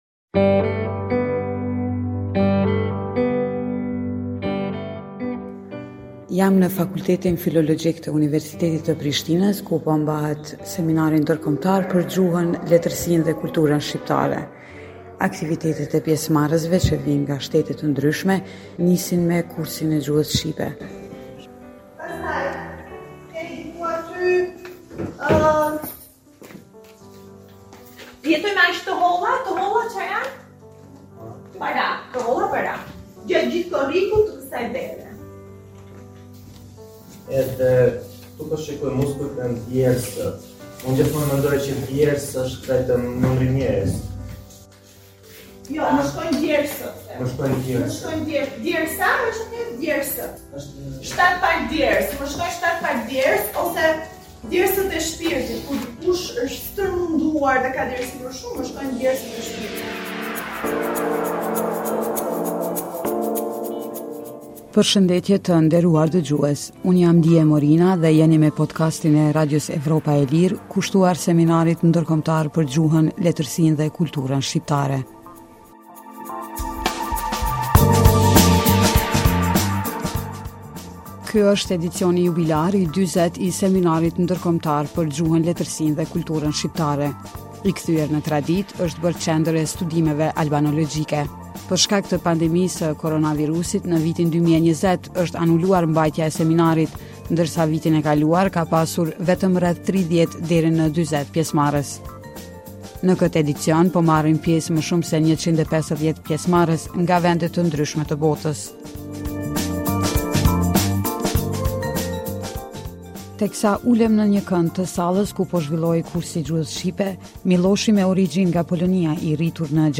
Në edicionin jubilar të 40-të të Seminarit Ndërkombëtar për Gjuhën, Letërsinë dhe Kulturën Shqiptare në Prishtinë, po marrin pjesë më shumë se 150 pjesëmarrës nga shtete të ndryshme të botës. Radio Evropa e Lirë ka folur me disa nga ta, të cilët tashmë flasin rrjedhshëm gjuhën shqipe.